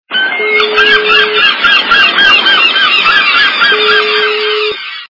» Звуки » Природа животные » Чайки - Чайки
Звук Чайки - Чайки